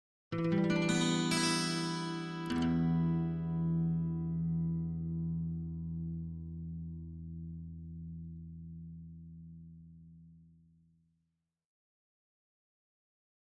Acoustic Guitar - Arpeggio 2 - E Minor Clean